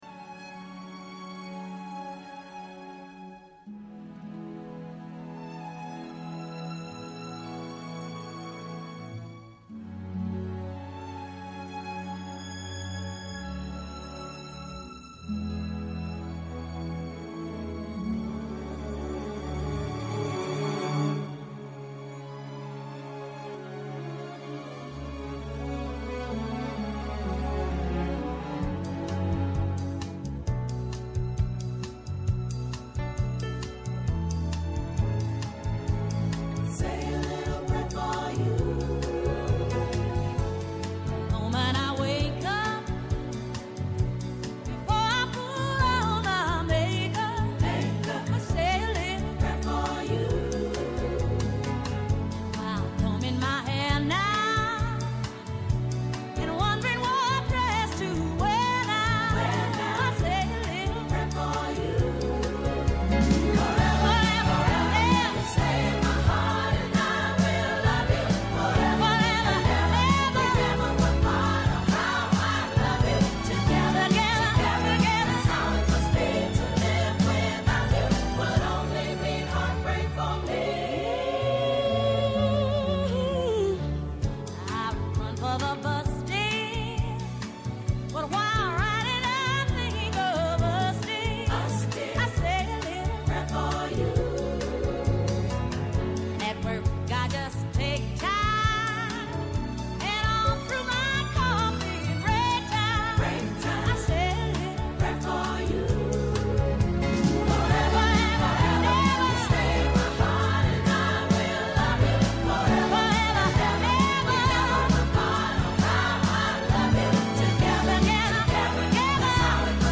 -Η Άννυ Ποδηματα, δημοσιογράφος
-Ο Δημήτρης Νανόπουλος, αστροφυσικός